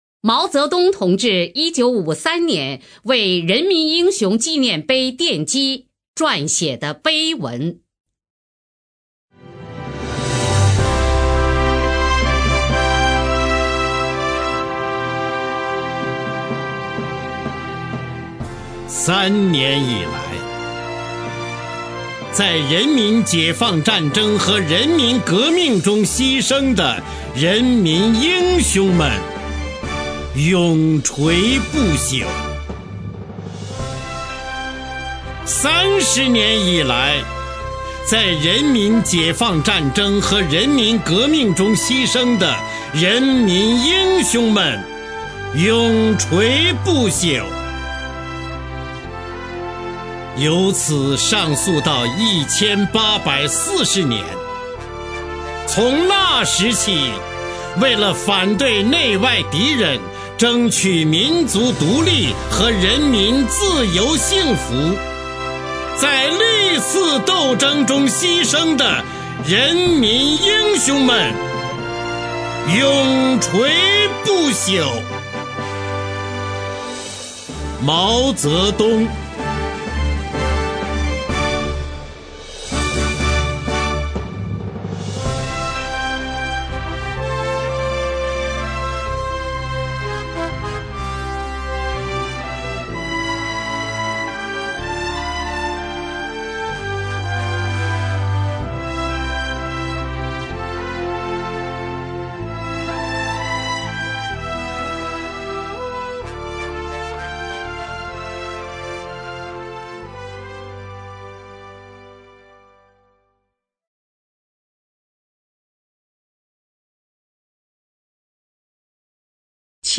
朗诵 方  明
朗诵 瞿弦和